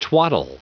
Prononciation du mot twaddle en anglais (fichier audio)
Prononciation du mot : twaddle